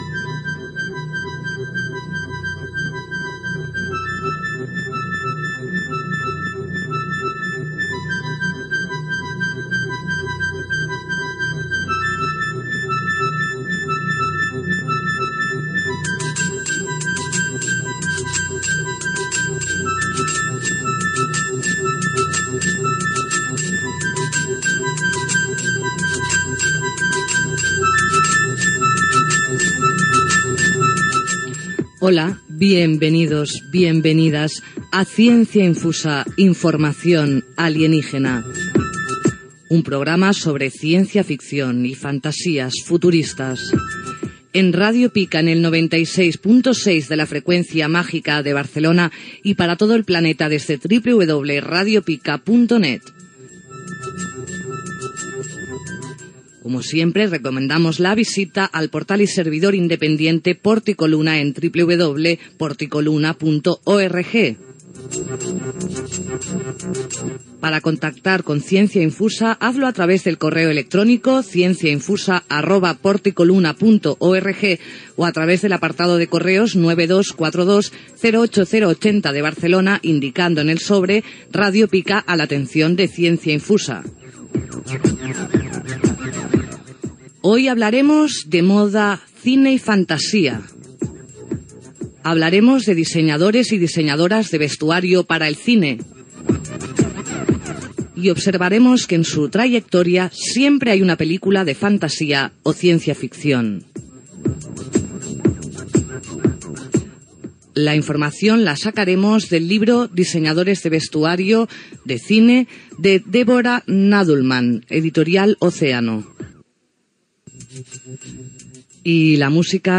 Benvinguda al programa, identificació de l'emissora, adreces a Internet de la ràdio i del programa, sumari de continguts, tema musical, el dissenyador de cinema James Acheson
FM